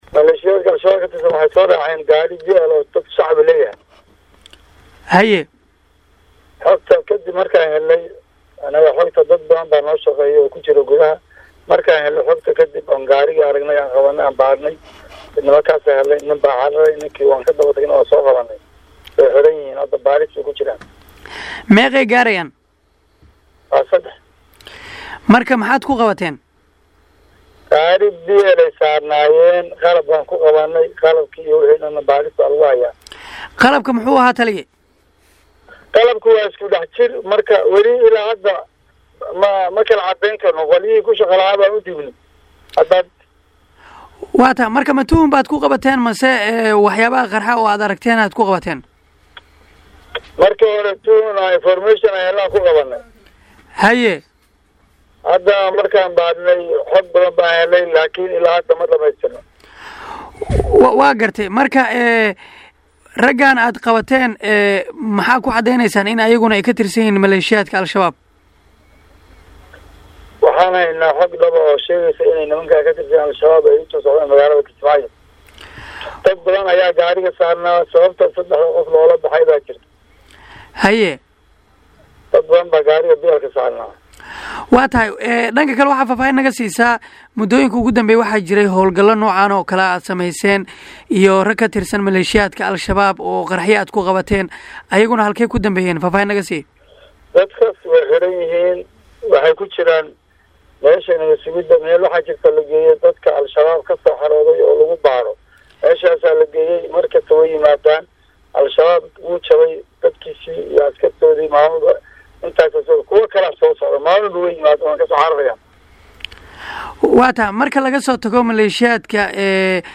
Halkaan Hoose ka Dhageyso Wareysiga Taliyaha https